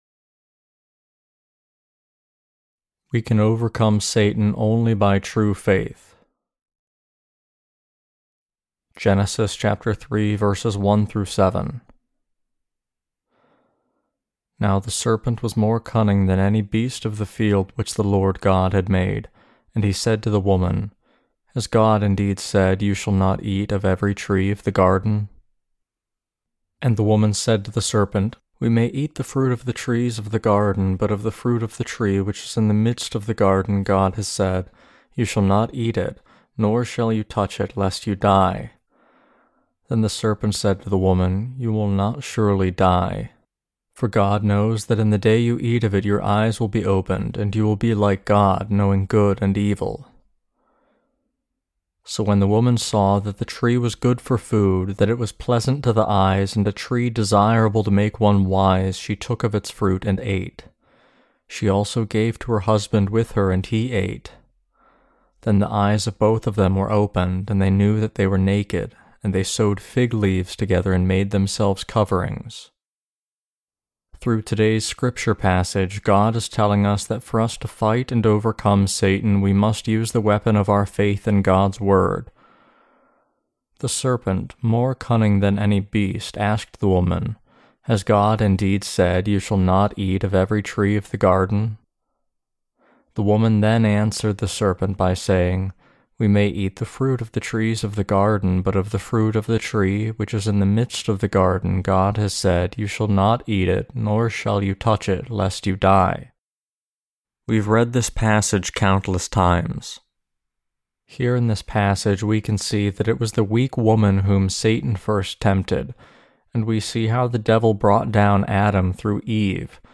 Sermons on Genesis (II) - The Fall of Man and The Perfect Salvation of God Ch3-5.